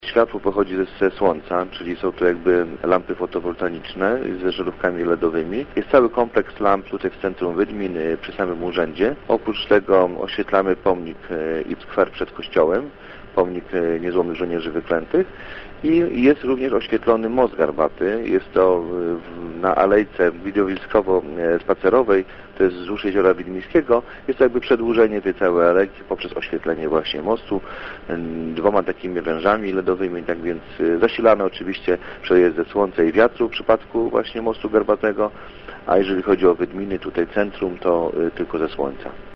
Mówi Radosław Król, wójt gminy Wydminy: